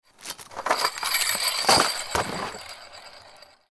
Unboxing Christmas Sleigh Bells Sound Effect
Taking Christmas Sleigh Bells with Wooden Handle Out of Box Sound Effect – hear the festive jingling as you unwrap and pick up sleigh bells with a wooden handle, creating a real and joyful holiday atmosphere.
Unboxing-christmas-sleigh-bells-sound-effect.mp3